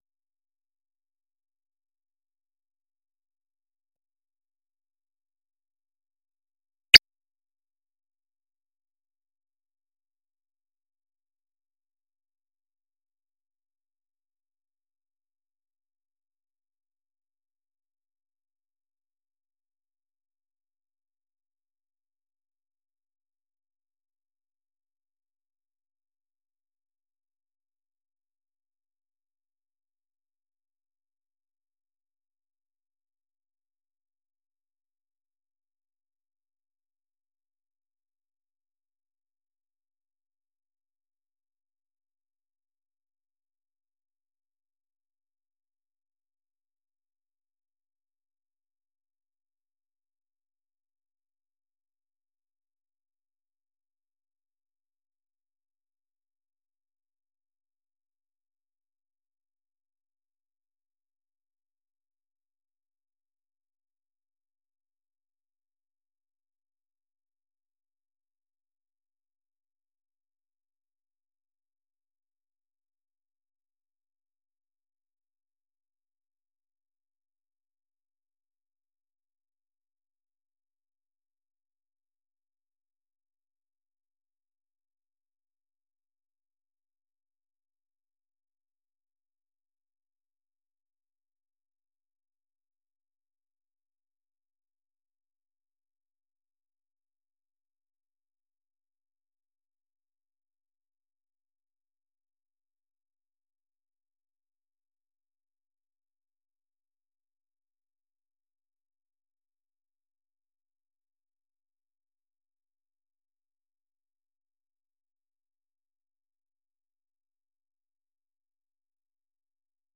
세계 뉴스와 함께 미국의 모든 것을 소개하는 '생방송 여기는 워싱턴입니다', 저녁 방송입니다.